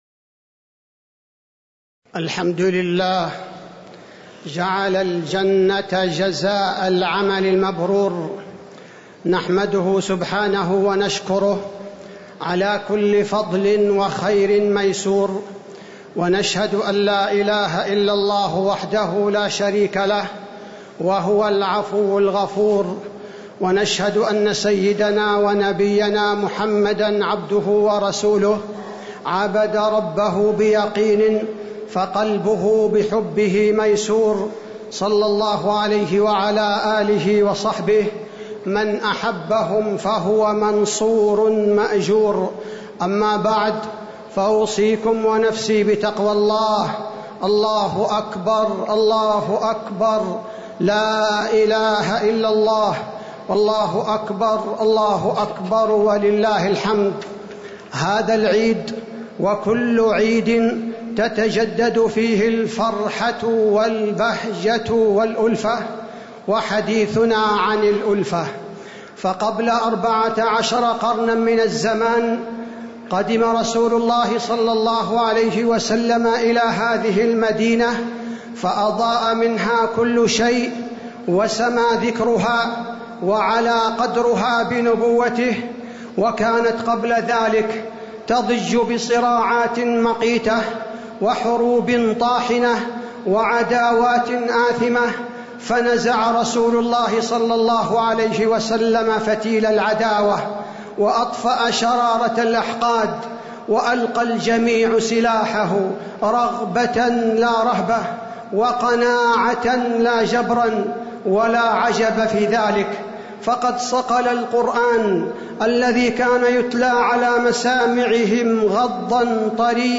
خطبة عيد الفطر- المدينة - الشيخ عبدالباري الثبيتي
تاريخ النشر ١ شوال ١٤٤٤ هـ المكان: المسجد النبوي الشيخ: فضيلة الشيخ عبدالباري الثبيتي فضيلة الشيخ عبدالباري الثبيتي خطبة عيد الفطر- المدينة - الشيخ عبدالباري الثبيتي